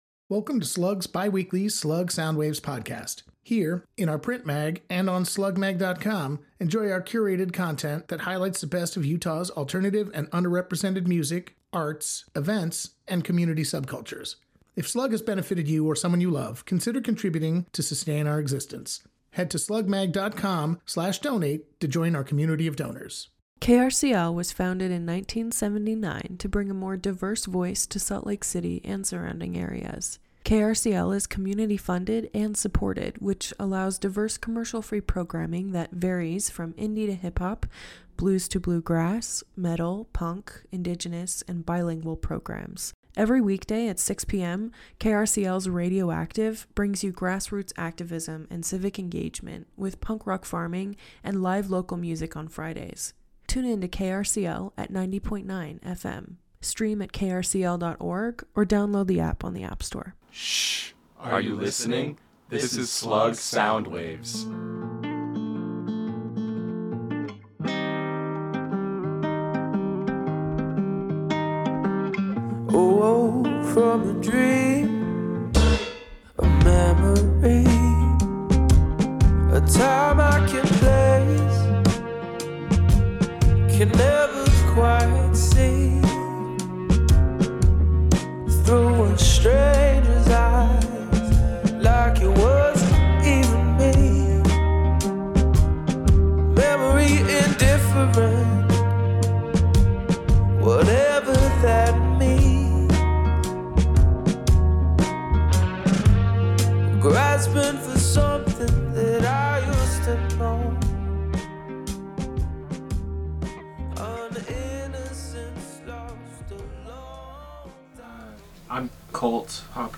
On this episode of SLUG Soundwaves, we chat with the band about their origin, sound and everything that went into making Little While the robust, beloved record it is.